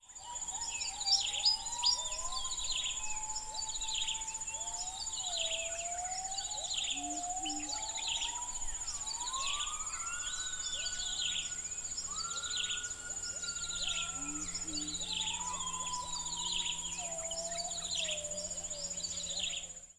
The dawn chorus
The audio clip is from a recording in shrub swamp. The bird calls featured include the following species: Rhipidura javanica, Copsychus saularis, Pycnonotus plumosus, Prinia flaviventris, Centropus sinensis
Shrub-swamp-sample.mp3